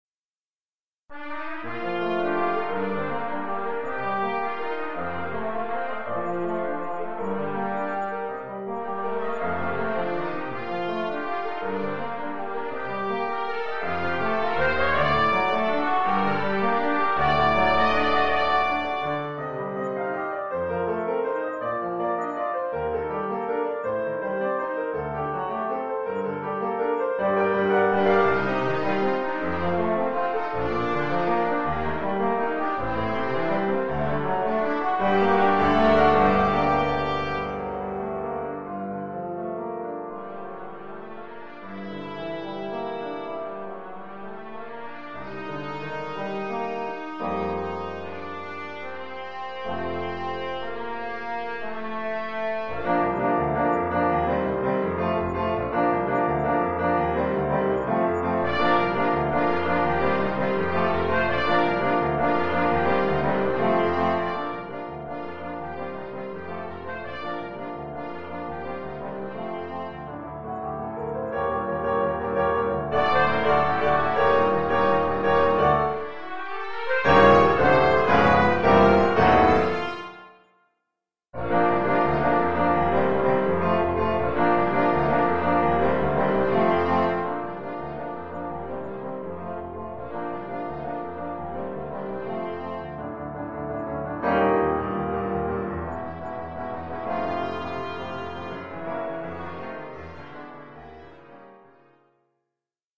Oeuvre pour trompette sib ou ut
ou cornet ou bugle et piano.
Niveau : milieu de 2e cycle.